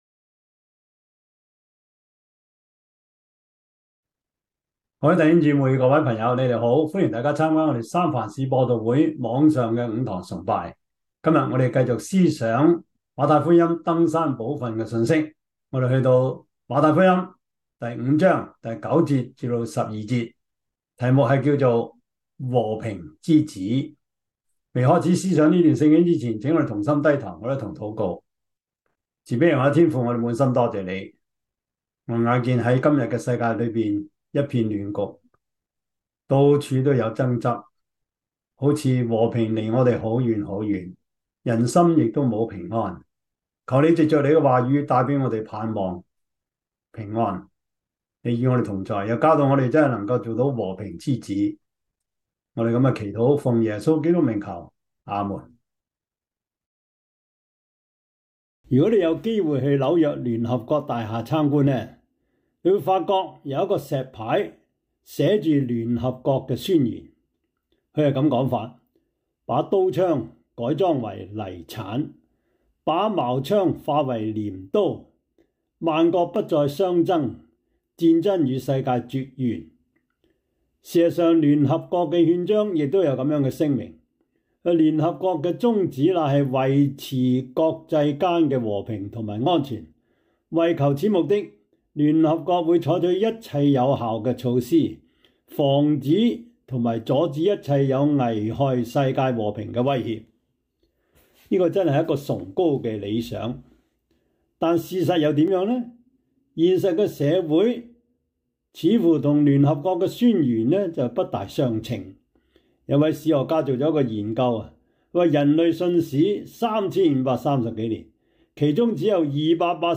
Topics: 主日證道 « 簡樸本色 (2) 愛的命令 »